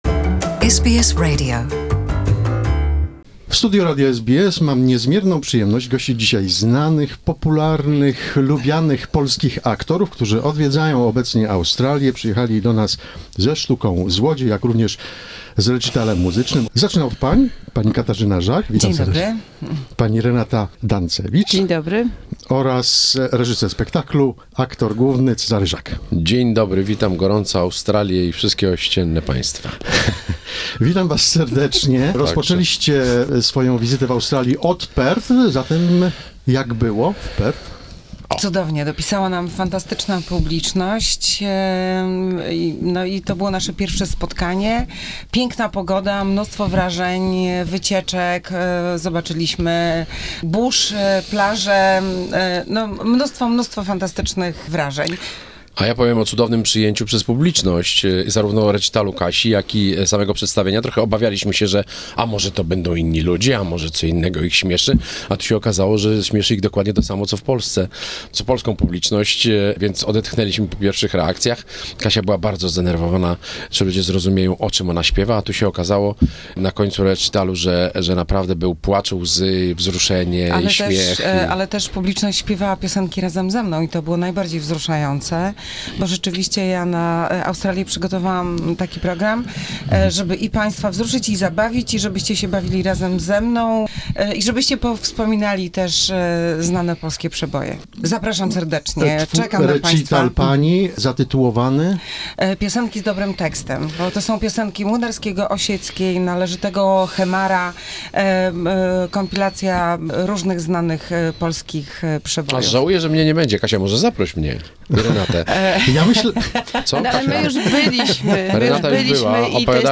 Well-know Polish actors, Katarzyna Żak, Renata Dancewicz and Cezary Żak visited SBS studio in Melbourne to share their experience about playing in the show "Theft' by Eric Chappell.